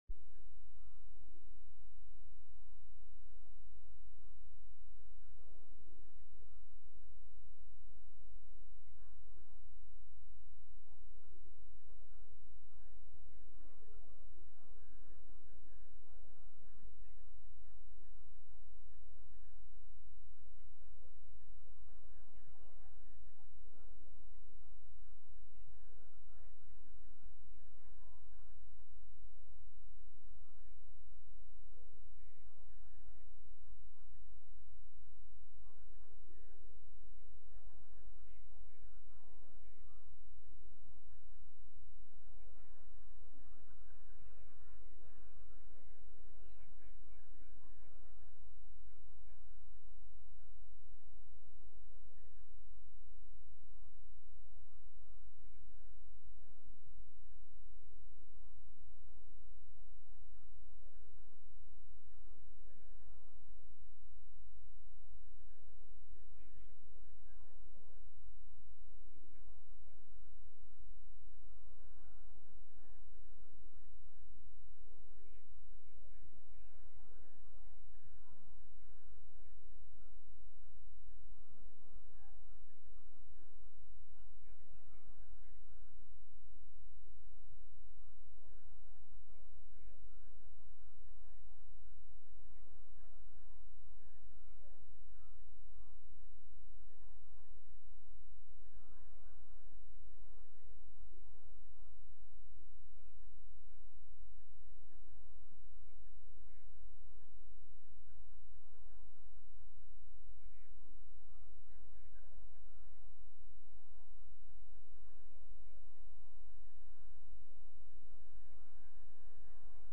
Sermons | Grace Lutheran Church
From Series: "Sunday Worship"